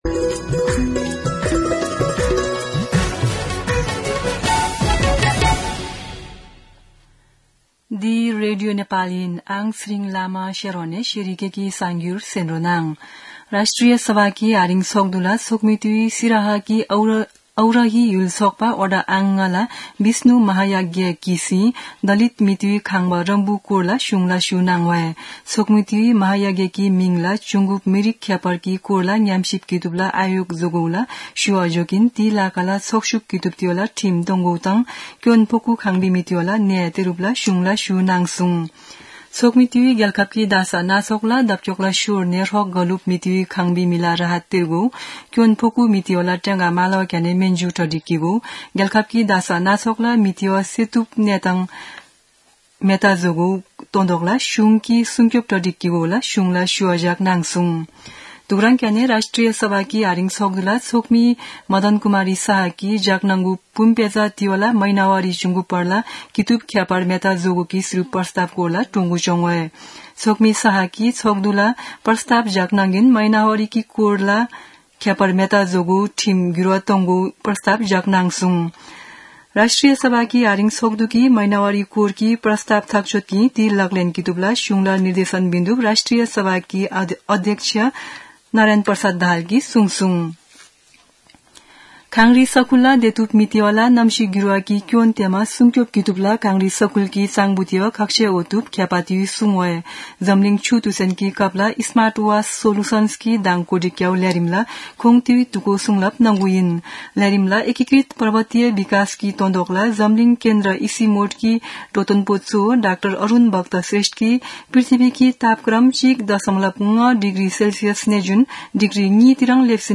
शेर्पा भाषाको समाचार : ८ चैत , २०८१
Sherpa-News-1.mp3